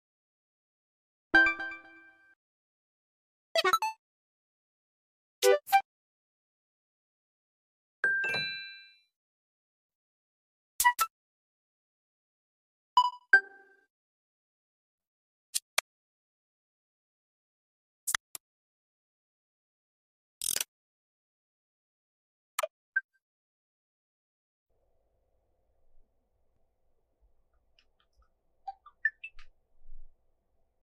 Nintendo showed off the new Switch 2 menu sounds on their X account today. It’s nice to have some sounds that are similar to Wii.